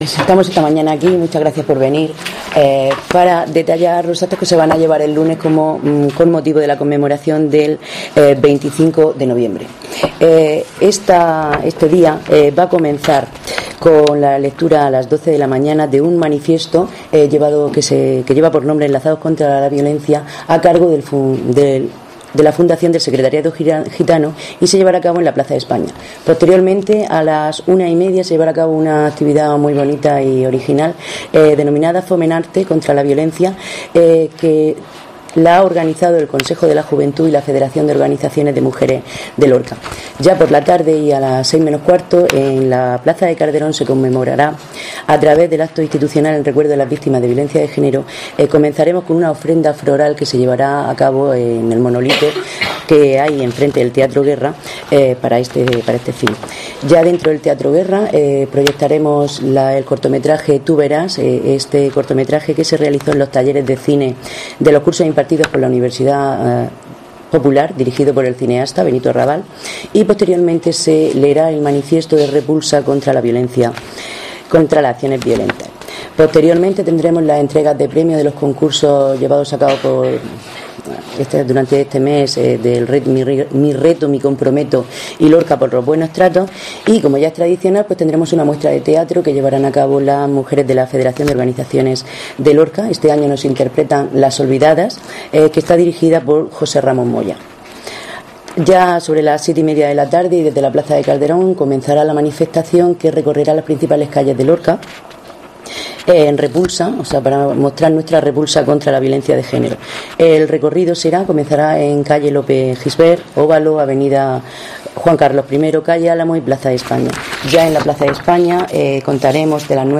Antonia Perez, edil Igualdad sobre actos 25N